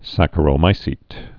(săkə-rō-mīsēt)